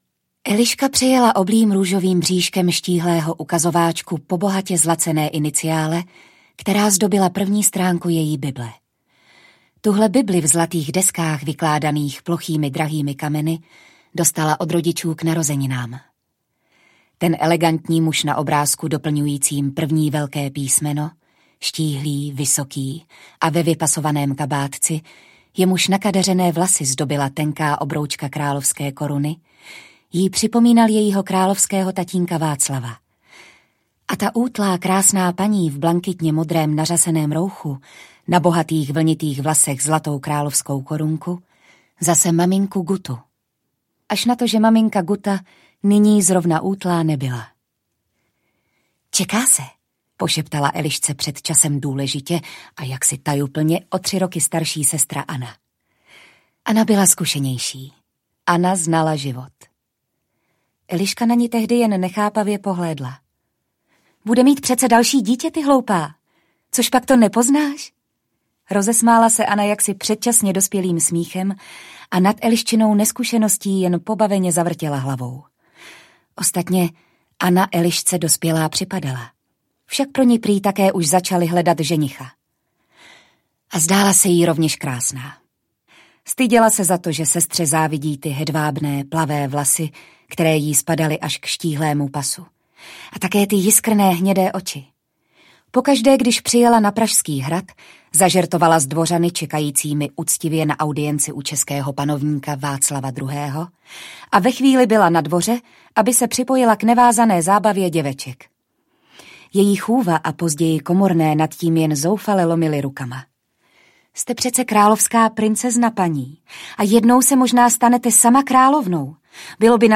Eliška Přemyslovna audiokniha
Ukázka z knihy